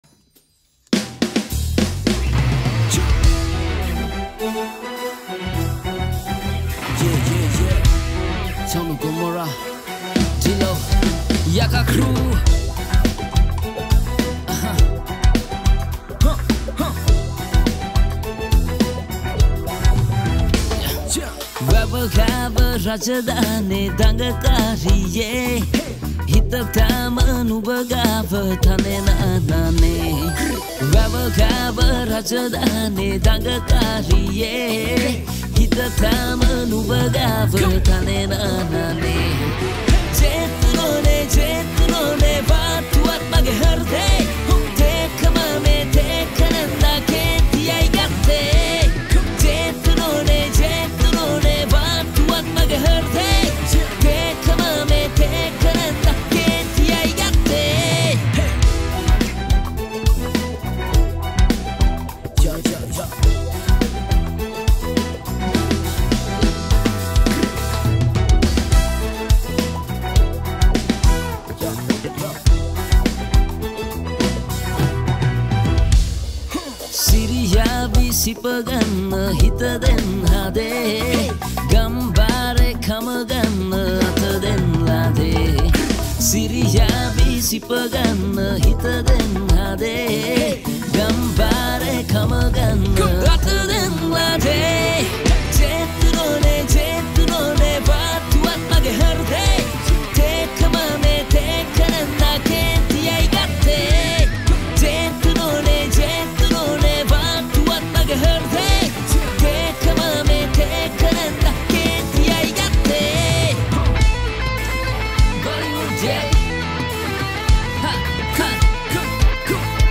Releted Files Of Sinhala Band Medley Songs